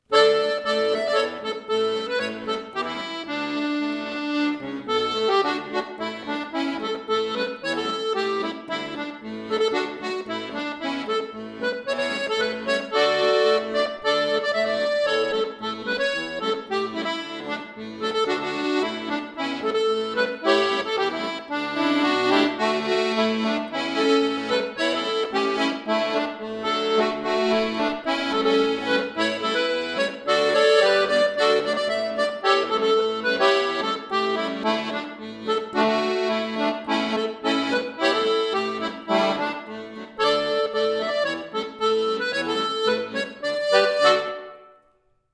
Folksong